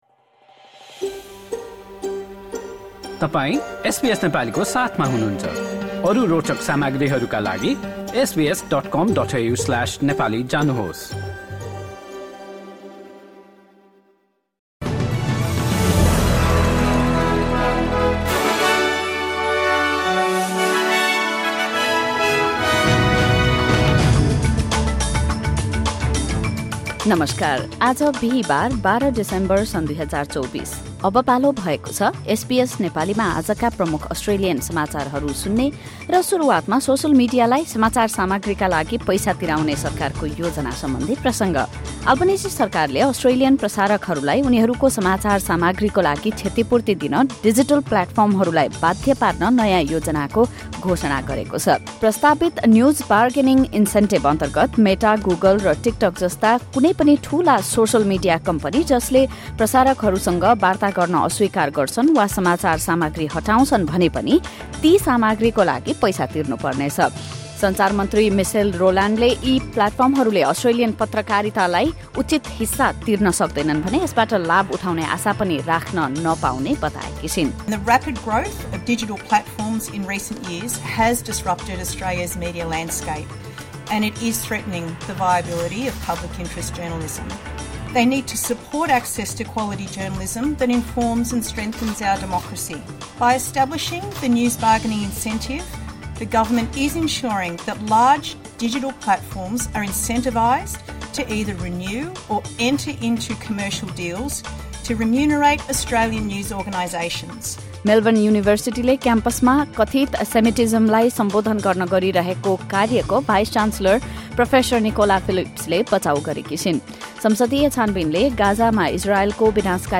Listen to the latest top news from Australia in Nepali.